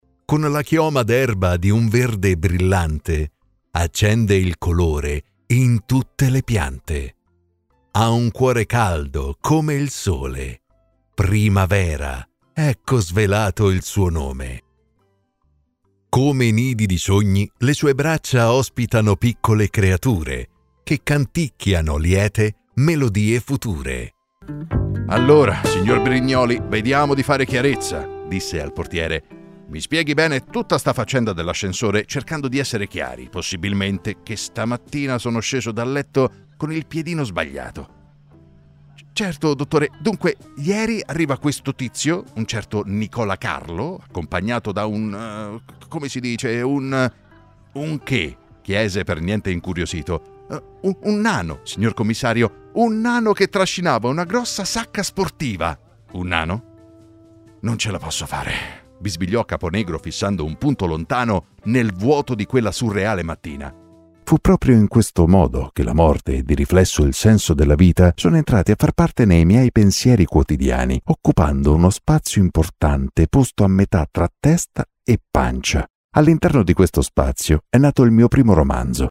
Audiobooks
My voice is deep, mature, warm and enveloping, but also aggressive, emotional and relaxing.